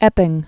(ĕpĭng)